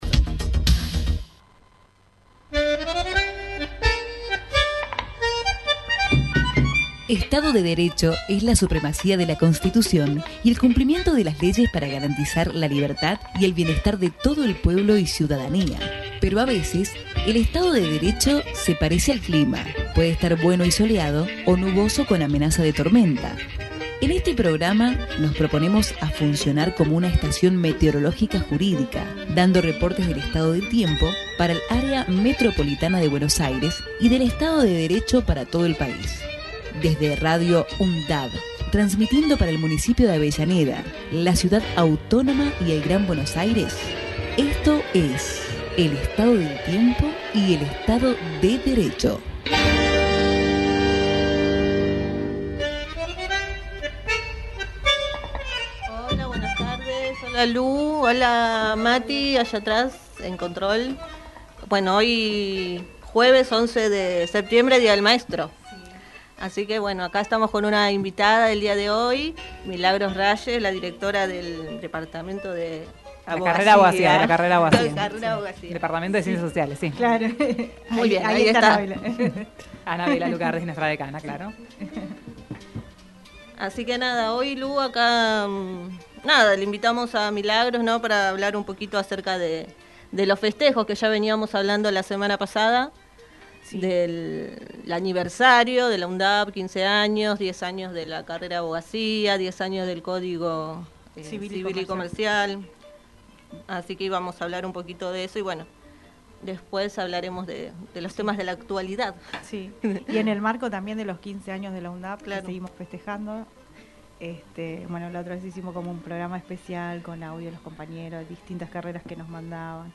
El Estado del Tiempo y el Estado de Derecho Texto de la nota: El Estado del Tiempo y el Estado de Derecho es un programa realizado por estudiantes y docentes de la carrera de Abogacía de la Universidad Nacional de Avellaneda, fue emitido por Radio UNDAV desde el año 2016 todos los jueves de 15 a 16hs.